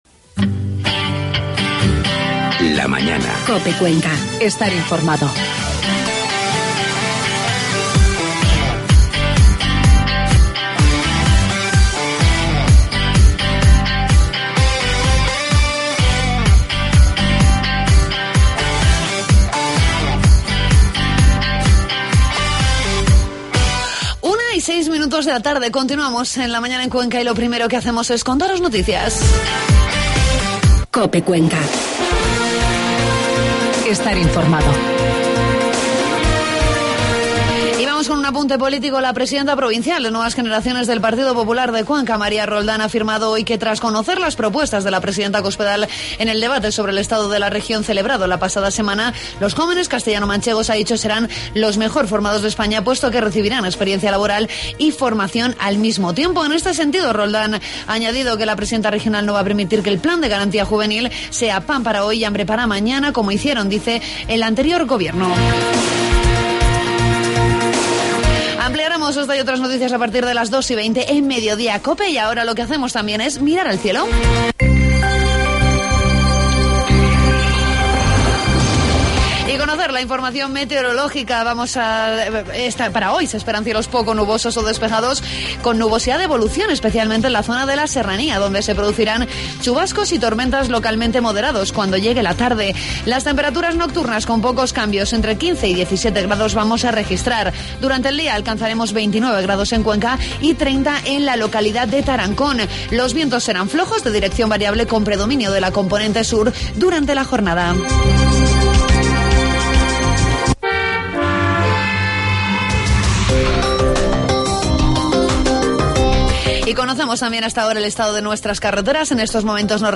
Entrevitsamos también al alcalde de la localidad de Campillo de Altobuey, Paco López, para conocer el programa de fiestas.